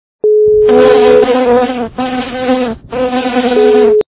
» Звуки » Природа животные » Назойливая - муха
При прослушивании Назойливая - муха качество понижено и присутствуют гудки.
Звук Назойливая - муха